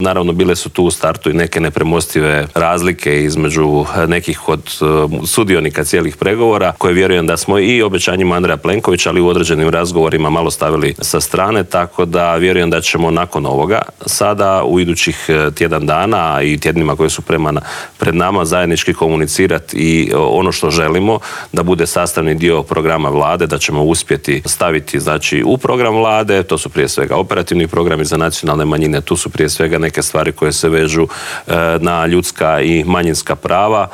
ZAGREB - Nakon što je predsjednik HDZ-a Andrej Plenković predao 78 potpisa i od predsjednika Zorana Milanovića dobio mandat da treći put zaredom sastavi Vladu, saborski zastupnik češke i slovačke nacionalne manjine Vladimir Bilek otkrio je u Intervjuu tjedna Media servisa tko je od manjinaca dao svoj potpis.